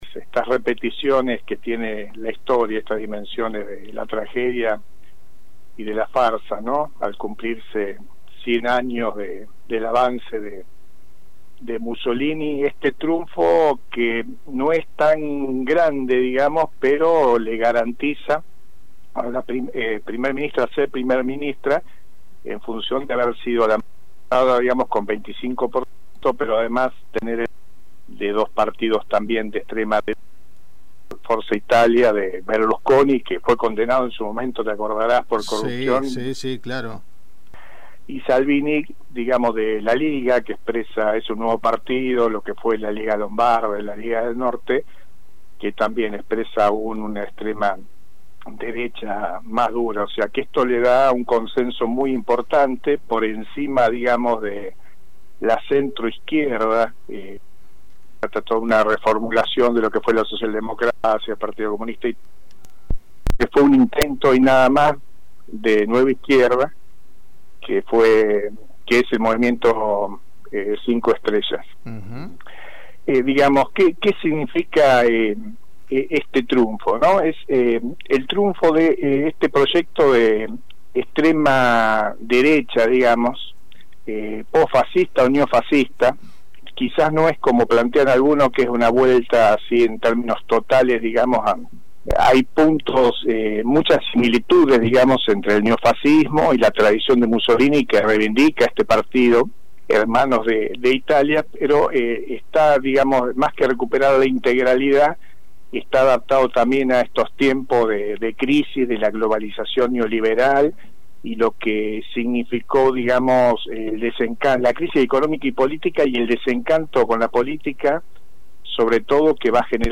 El lema «Dios, patria y familia» utilizado en la campaña electoral se asemeja al utilizado por distintas figuras políticas nacionales que han sabido capitalizar el descontento social y el descreimiento al sistema político. Escuchá la entrevista completa